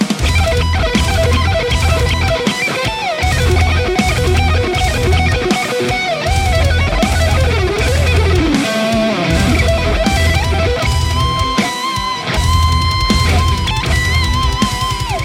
It's fully loaded for any Hi-Gain application. 100 Watts of sofisticated Rock and Metal tones, It's all about gain!
Lead Mix
RAW AUDIO CLIPS ONLY, NO POST-PROCESSING EFFECTS